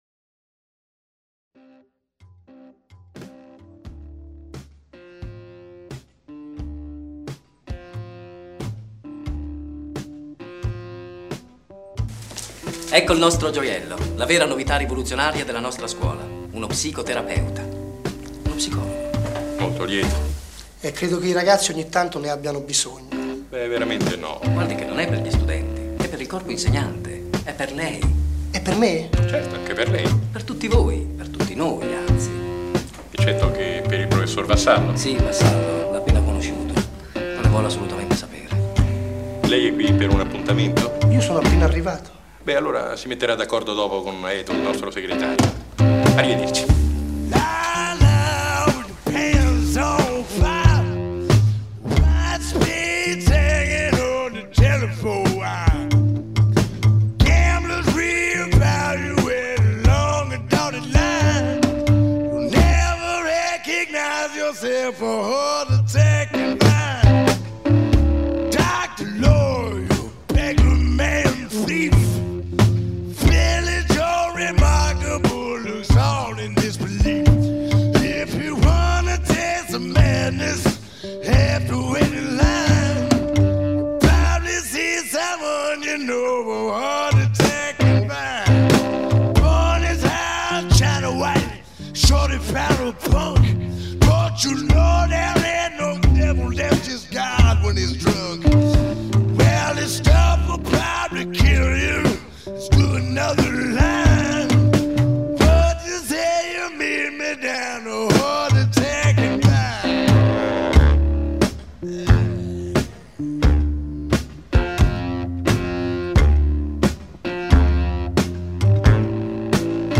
Riporto un dialogo tratto dal film Bianca, diretto ed interpretato da Nanni Moretti, del 1984.